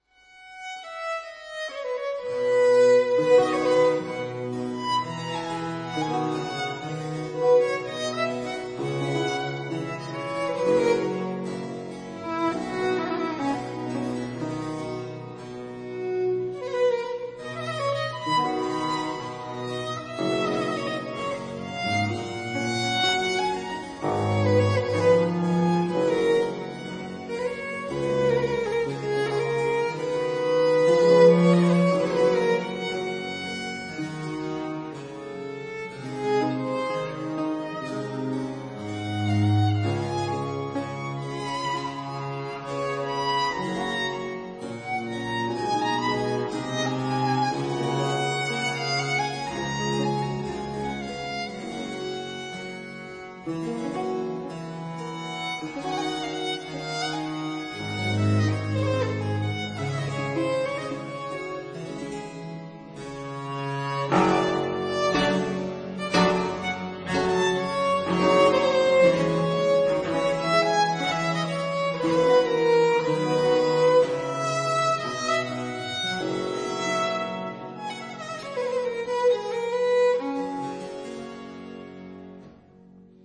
這張專輯裡，雖是以小提琴、大提琴、大魯特琴、大鍵琴為主的四重奏，
但音樂主角很明顯還是在小提琴。